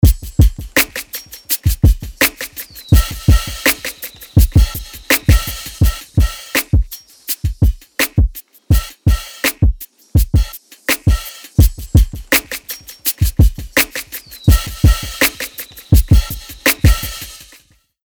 MicroPitchは、サウンドを自然に太くする高解像度ピッチシフトと、ドラマチックなスラップバックを生み出すディレイを融合したプラグインです。
MicroPitch | Drum Loop | Preset: Slurred Synth Slap
MicroPitch-Eventide-Drum-Loop-Slurred-Synth-Slap.mp3